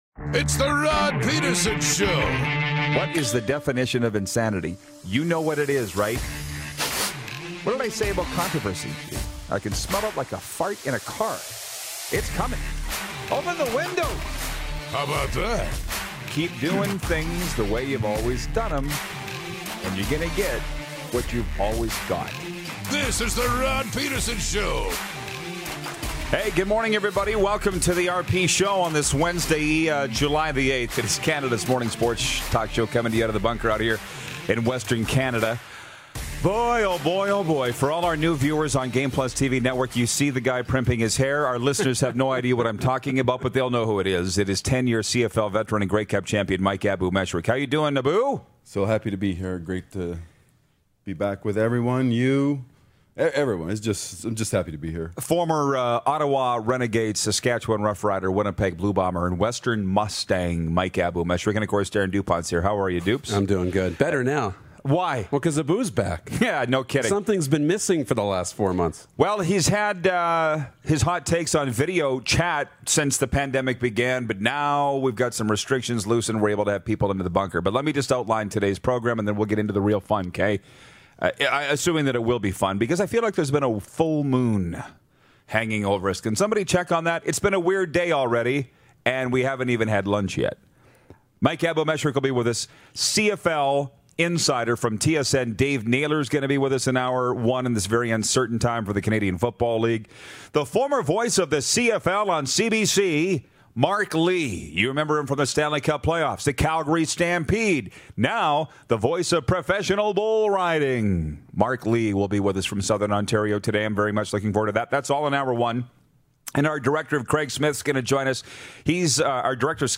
Former CFL/Hockey Night in Canada/Olympics Voice and Gemini Award Winning Sportscaster… Mark Lee gives us a call!